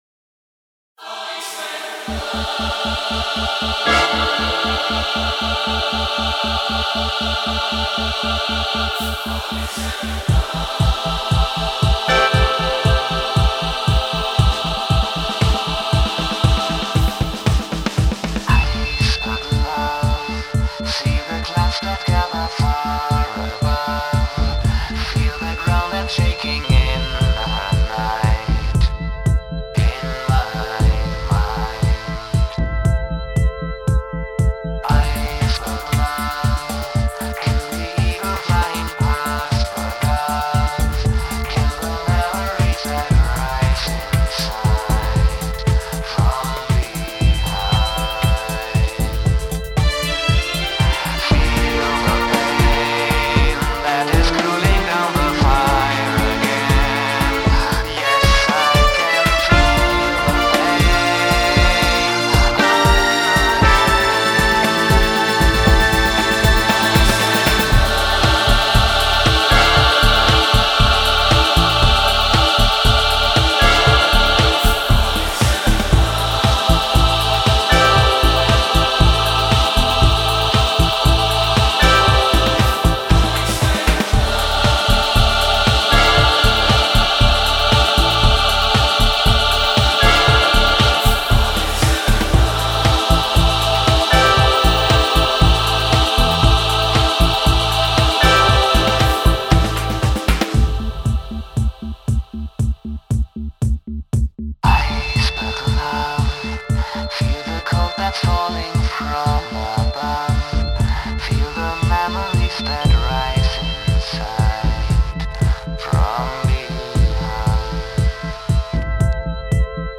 20 year later, I made a completely new recording of it with plugin orchester and virtual choir.